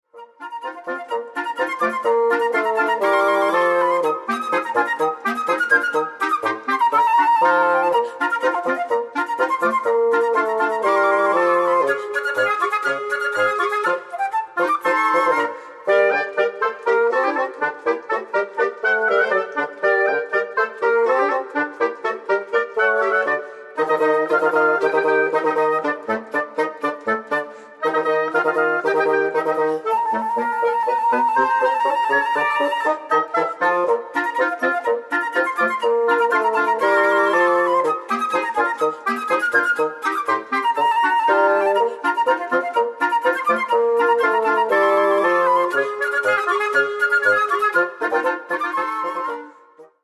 Classical Woodwind